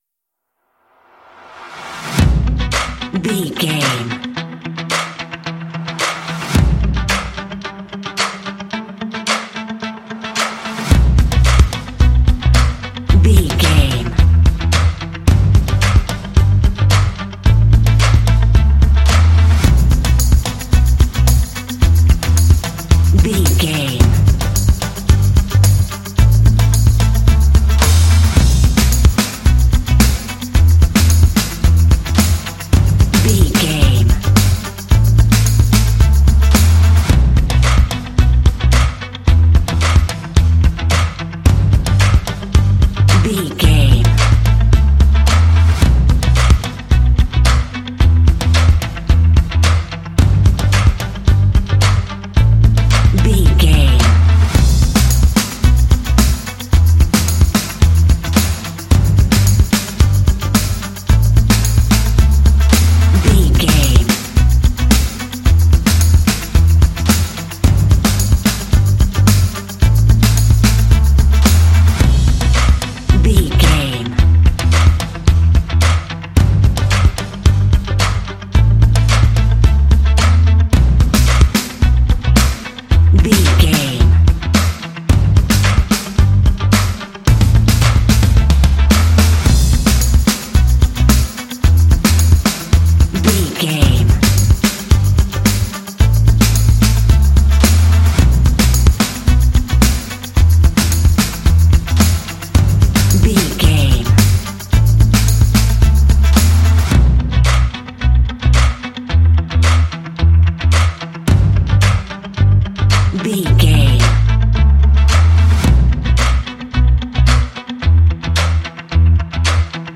Aeolian/Minor
cool
energetic
motivational
dramatic
drums
bass guitar
rock
alternative rock
indie